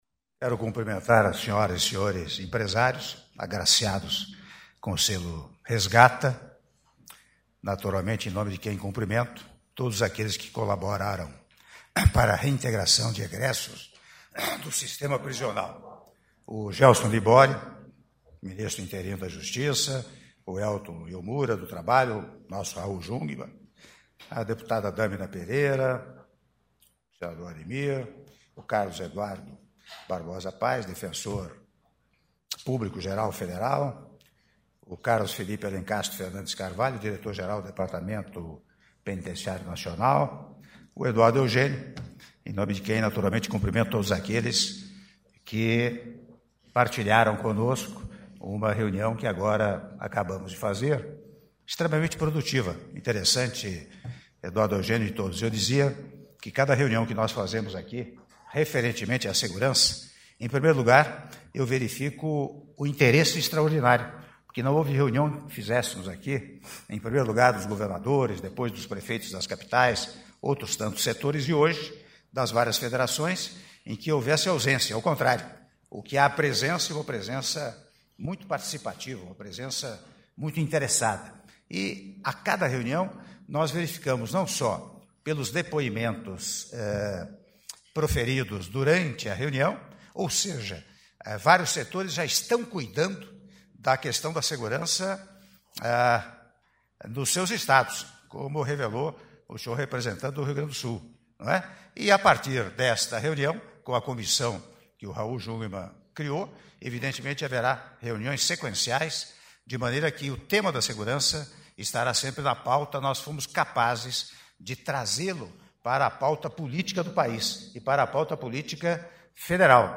Áudio do discurso do Presidente da República, Michel Temer, durante cerimônia de entrega do Selo Nacional de Responsabilidade Social pelo Trabalho no Sistema Prisional (Selo Resgata) - Palácio do Planalto - (08min15s)